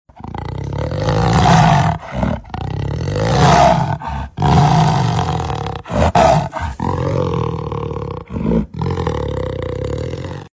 звуки животных